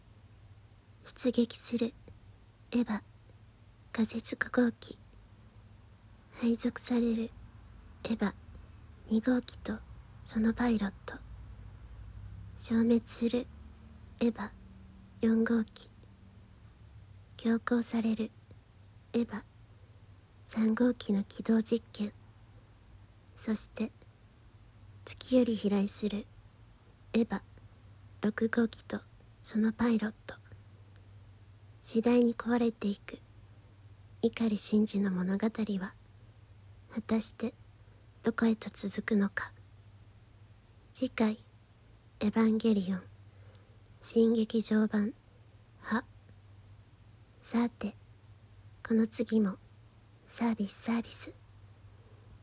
エヴァンゲリヲン 次回予告（綾波風ver.）